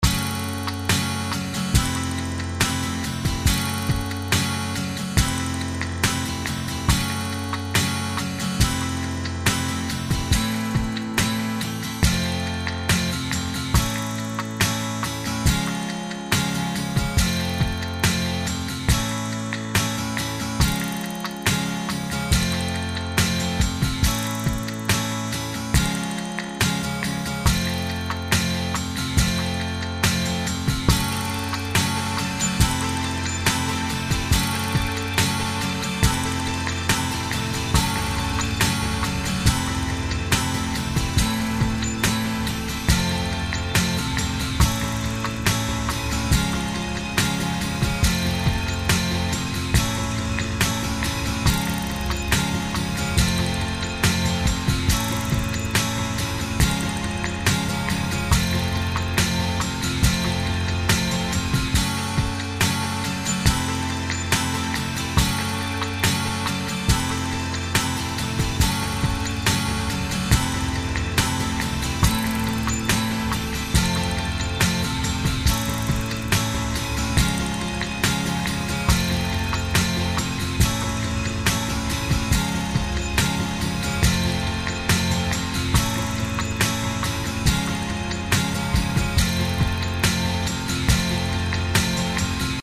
demo-4是在demo-2的基础上制作的，在Akkord Guitar上增加了一些失真效果。
背景来自Reaktor5。
鼓来自Synthetic Drums 2和Live5。